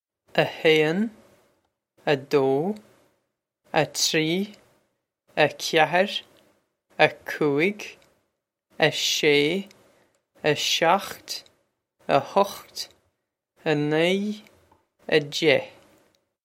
Pronunciation for how to say
a hay-n, a doh, a tree, a kya-her, a koo-ig, a shay, a shokht, a huckht, a nee, a jeh
This is an approximate phonetic pronunciation of the phrase.